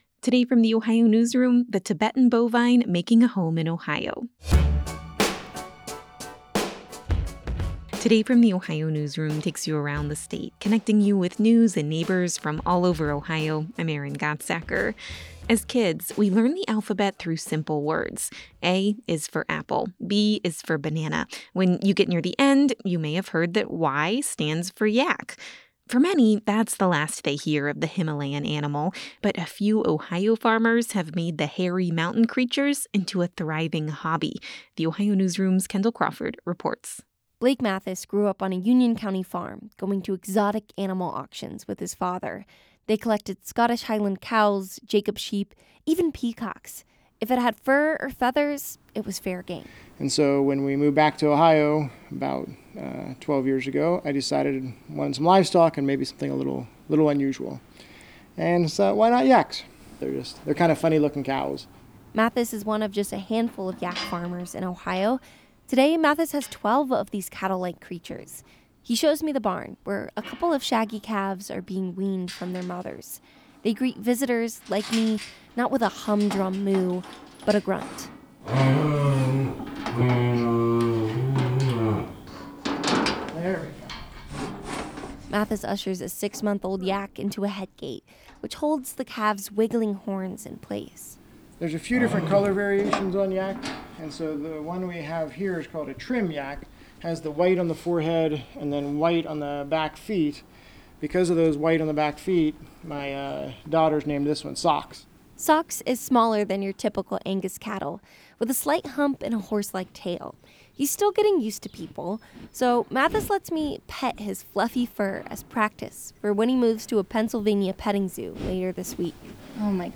They greeted me not with a humdrum moo, but a prolonged grunt.